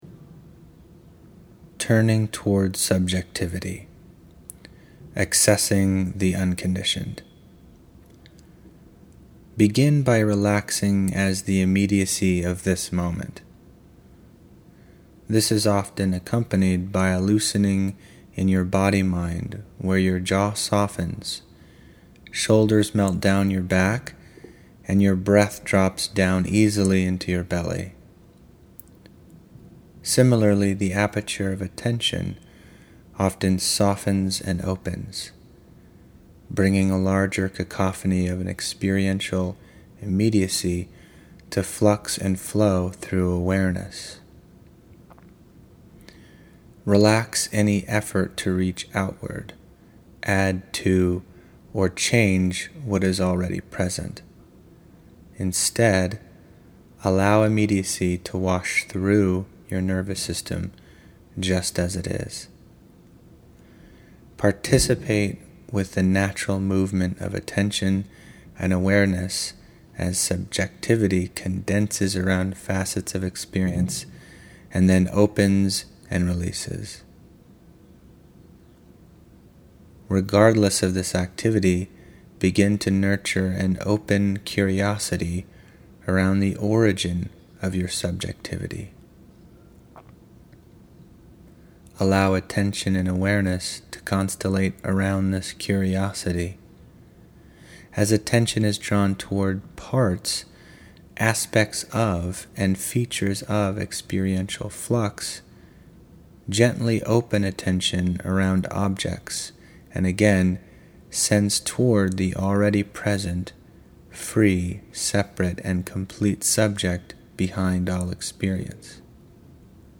Narrated exercises from Chapter Seven, The Paradox of Completeness: